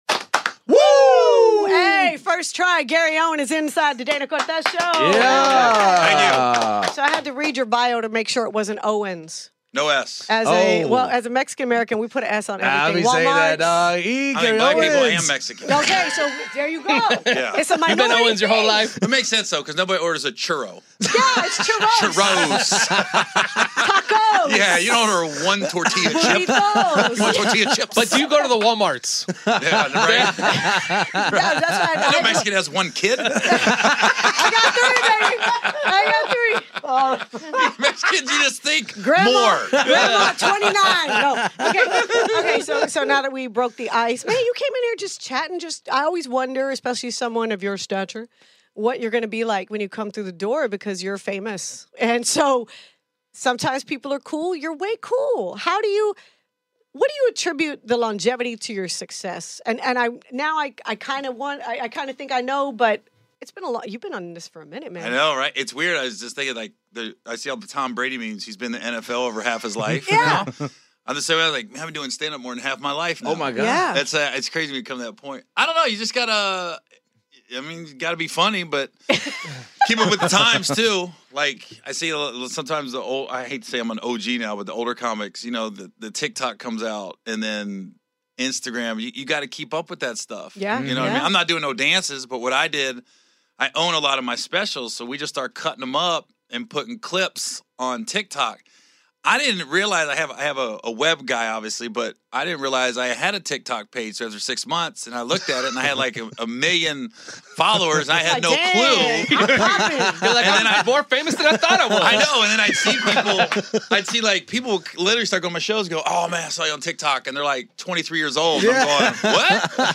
DCS Interviews Comedian Gary Owen